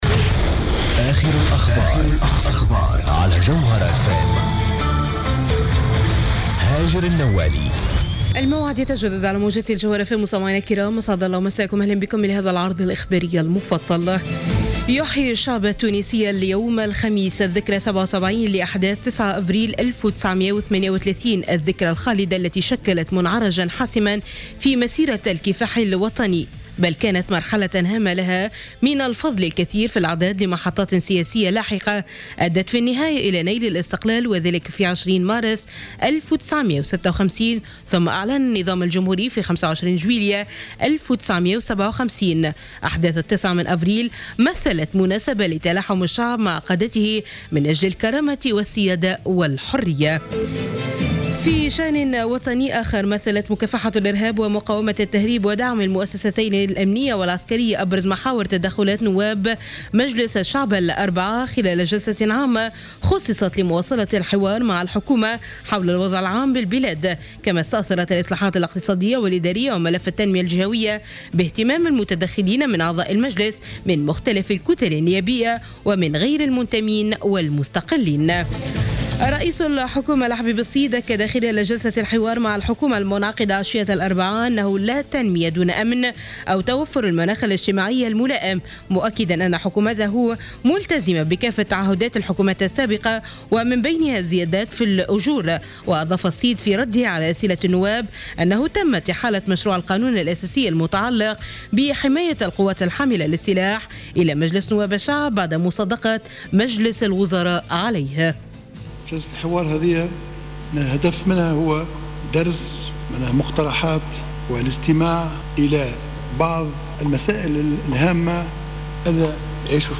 نشرة أخبار منتصف الليل ليوم الخميس 9 أفريل 2015